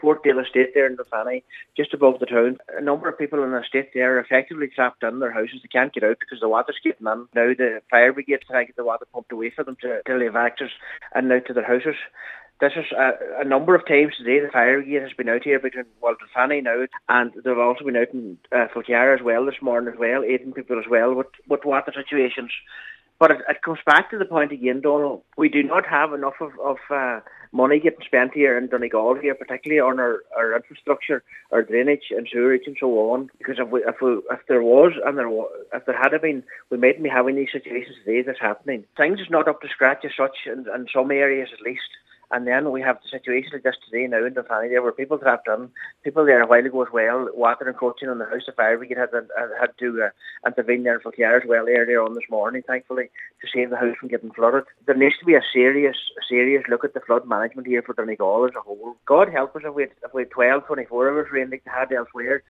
Cllr  Michael Mc Clafferty is Cathaoirleach of the Glenties Municipal District.